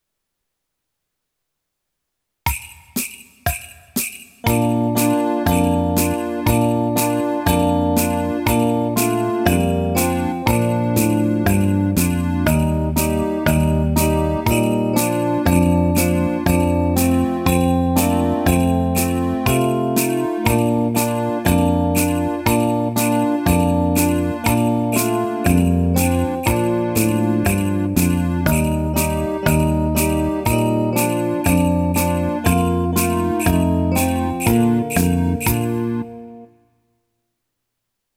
5th Grade Instrumental / Solo Accompaniment Tracks